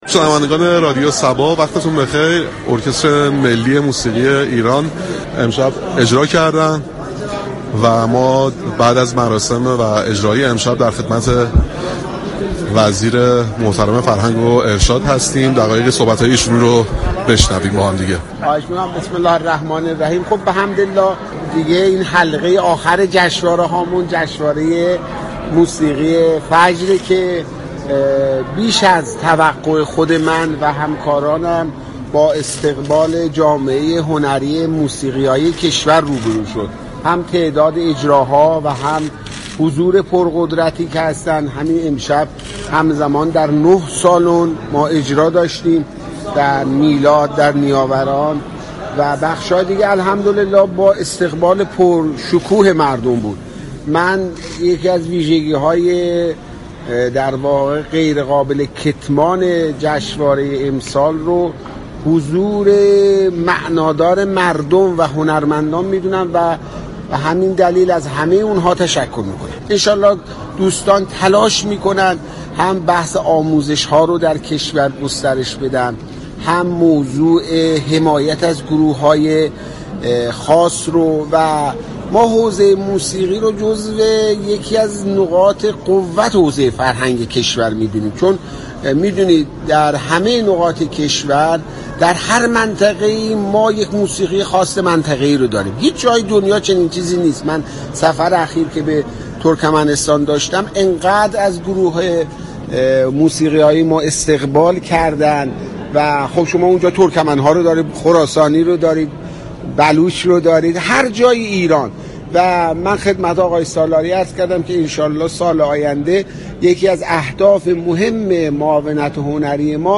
برنامه موسیقی محور «صباهنگ» با حضور در محل برگزاری جشنواره موسیقی فجر توضیحات وزیر ارشاد درباره جشنواره موسیقی فجر را به اطلاع مخاطبان رساند